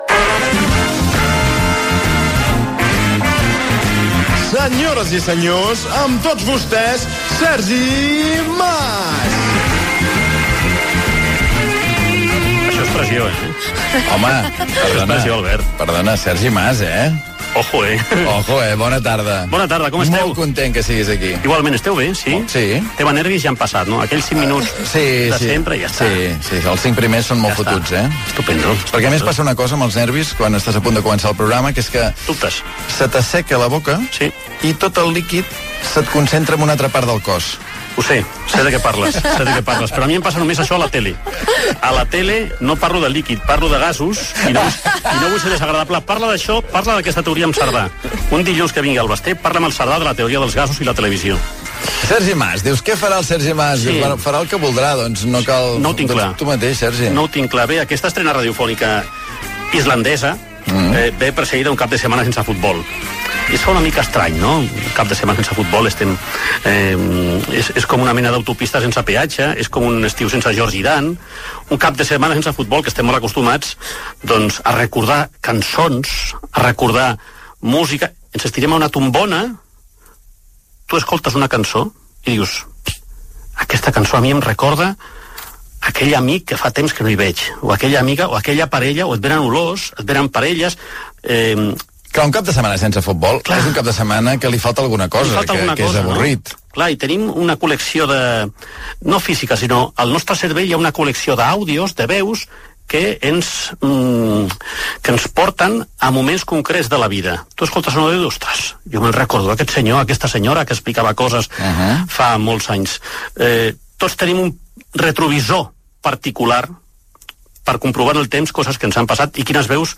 9ee25ef0756de1b9beaba97df3af9ea61bbf192a.mp3 6d02a5317b8a71fefabc74e9e8b16114e1c2dd5d.mp3 Títol RAC 1 Emissora RAC 1 Barcelona Cadena RAC Titularitat Privada nacional Nom programa Islàndia Descripció Primera edició del programa, comentari sobre les 7 del vespre. Secció de Sergi Mas amb retalls radiofònics de transmissions esportives
Gènere radiofònic Entreteniment